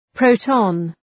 Προφορά
{‘prəʋtɒn}
proton.mp3